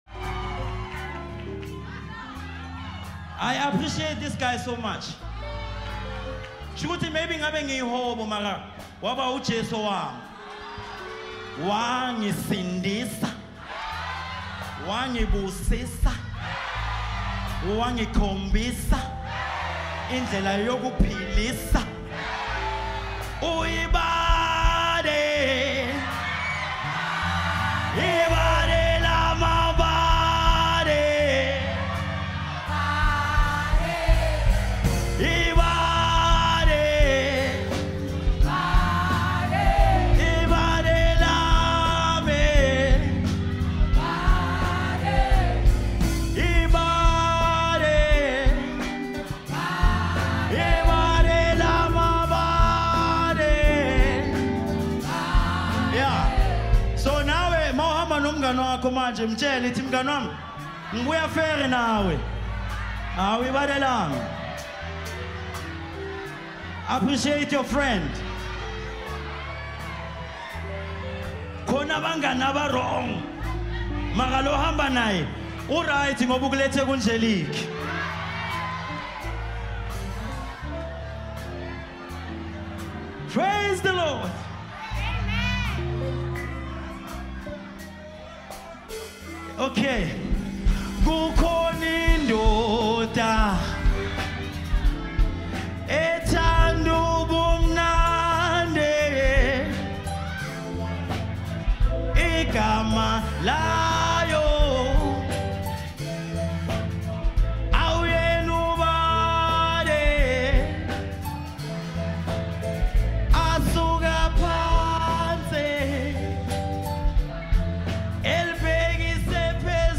Talented vocalist